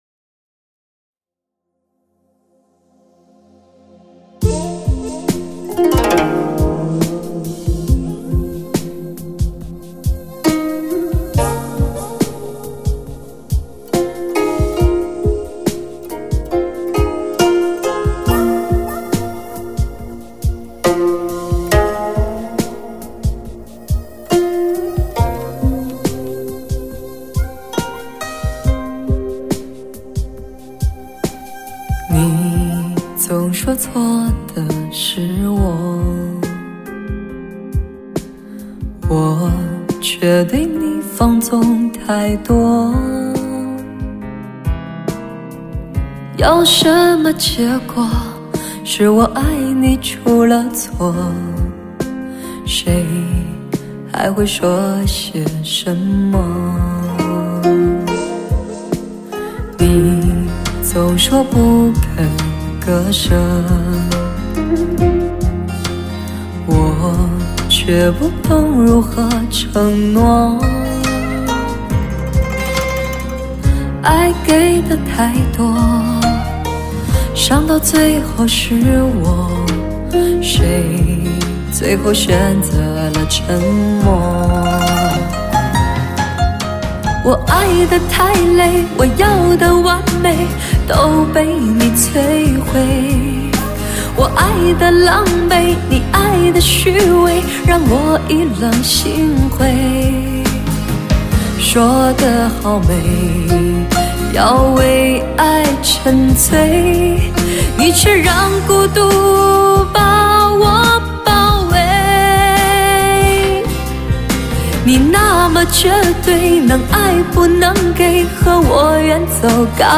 首首伤感情歌